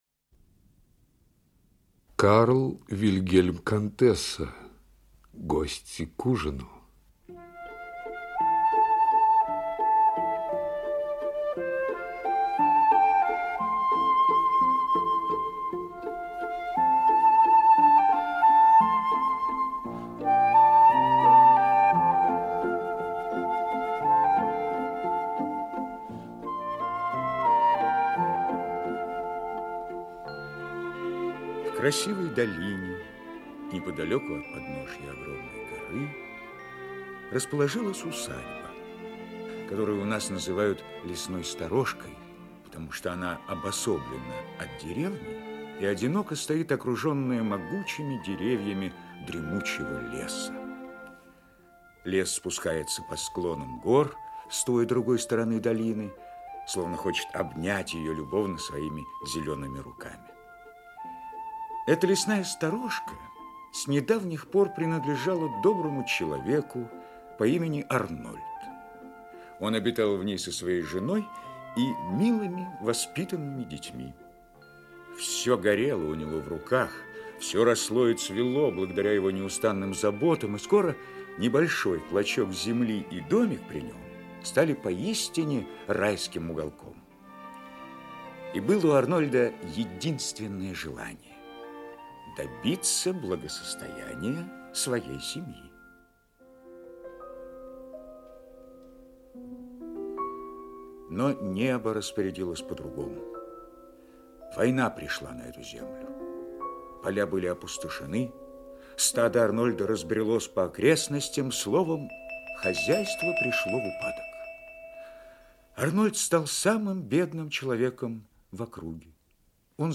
Аудиокнига Гости к ужину | Библиотека аудиокниг